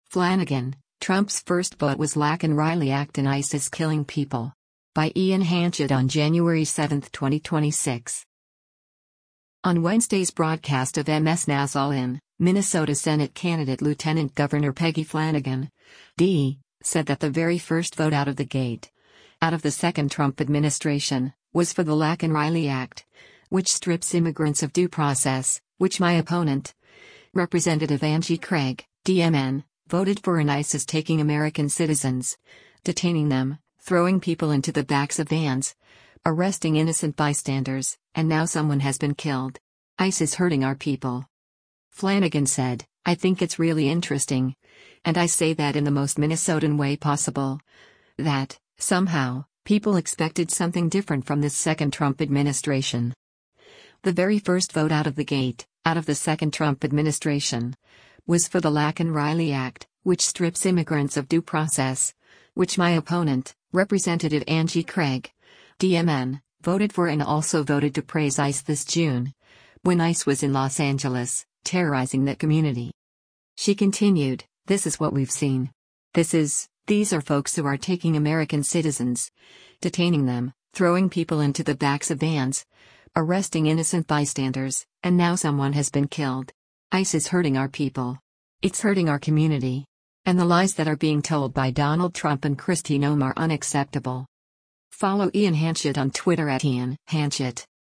On Wednesday’s broadcast of MS NOW’s “All In,” Minnesota Senate candidate Lt. Gov. Peggy Flanagan (D) said that “The very first vote out of the gate, out of the second Trump administration, was for the Laken Riley Act, which strips immigrants of due process, which my opponent, Rep. Angie Craig (D-MN), voted for” and ICE is “taking American citizens, detaining them, throwing people into the backs of vans, arresting innocent bystanders, and now someone has been killed. ICE is hurting our people.”